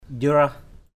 /ʄrʊah/ (d.) con mang đỏ = chevreuil.